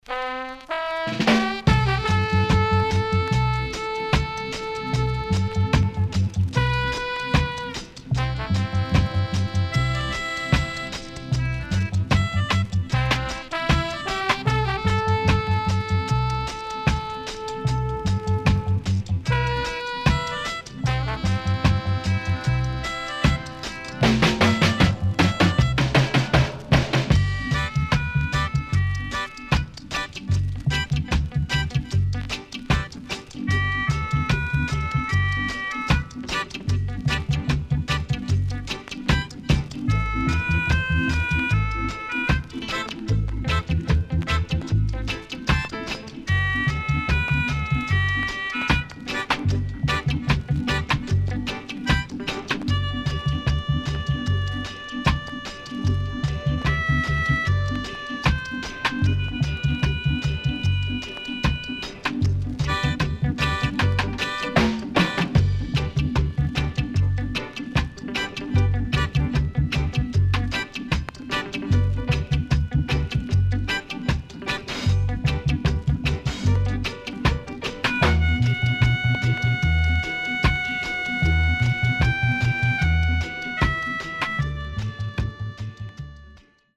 HOME > REGGAE / ROOTS  >  INST 70's
Guitar & Melodica Cool Roots Inst
SIDE A:所々チリノイズがあり、少しプチノイズ入ります。